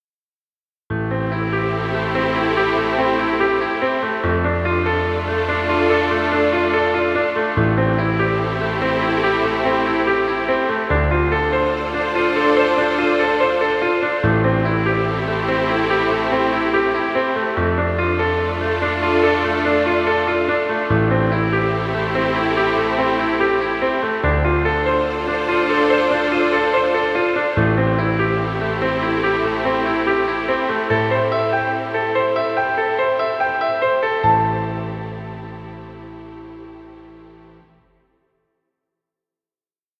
Romantic cinematic music.